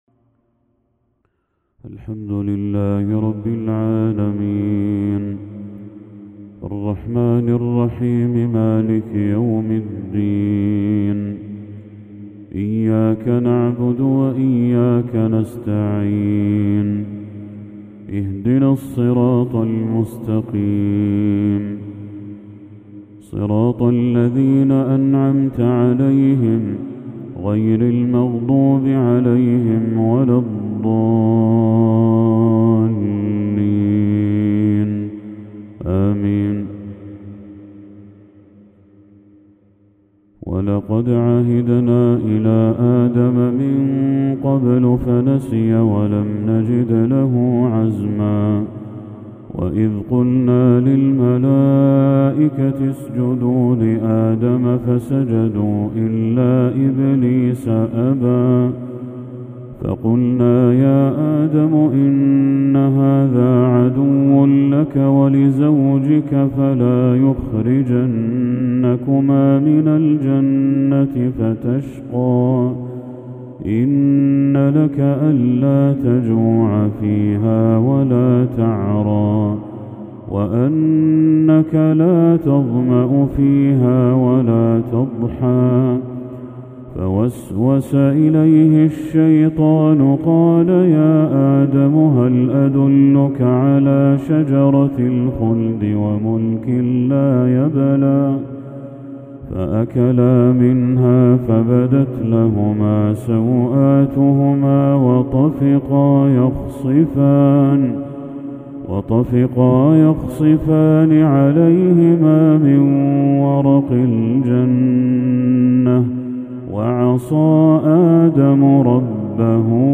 تلاوة تفيض جمالًا للشيخ بدر التركي خواتيم سورة طه | عشاء 21 ذو الحجة 1445هـ > 1445هـ > تلاوات الشيخ بدر التركي > المزيد - تلاوات الحرمين